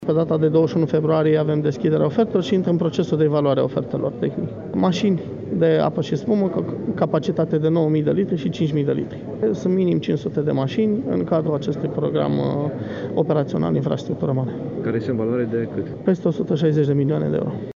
Şeful Inspectoratului General pentru Situaţii de Urgenţă, Daniel Dragne a fost prezent la Arad la prezentarea bilanţului ISU Arad pe 2017, el apreciind  activitatea salvatorilor arădeni ca fiind foarte bună.